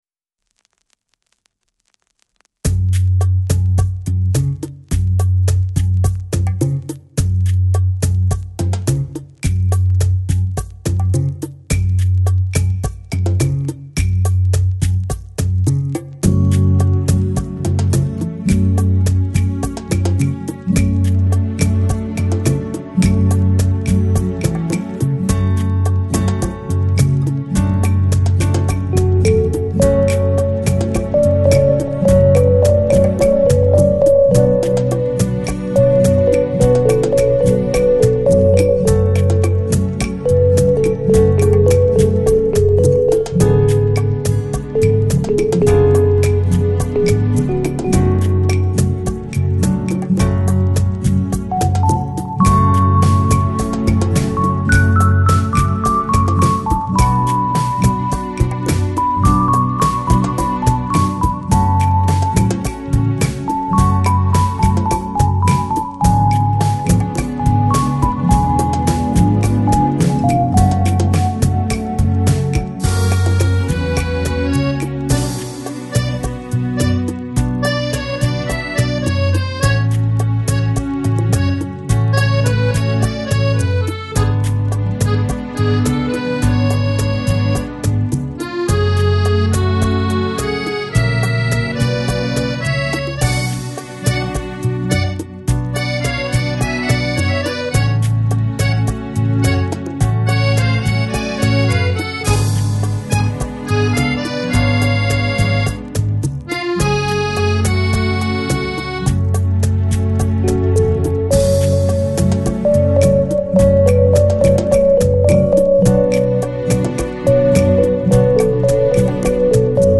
Electronic, Lounge, Chill Out, Downtempo, Balearic